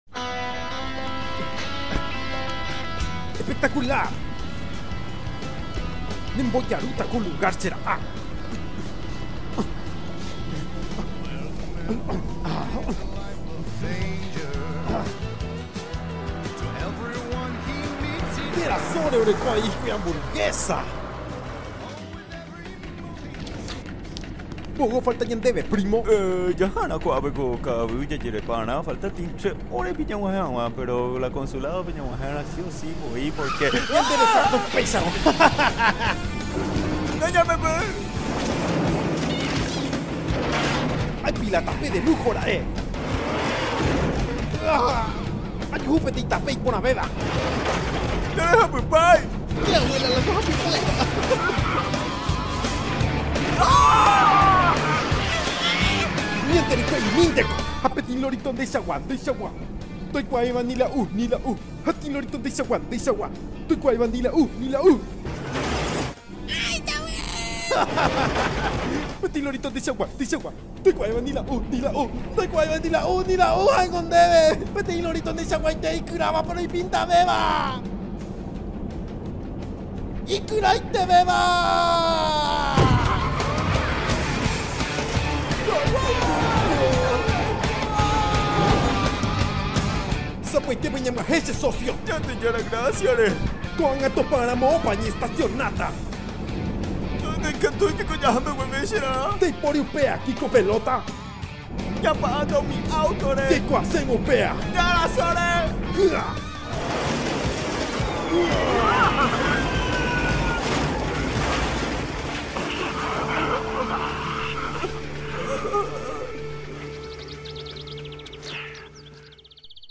Ace Ventura Doblajes en Guaraní.